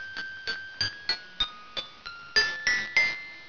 on the mallets